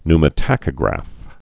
(nmə-tăkə-grăf, ny-) or pneu·mo·tach·y·graph (-tabreve;kē-)